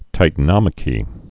(tītə-nŏmə-kē, tĭ-tănə-măkē)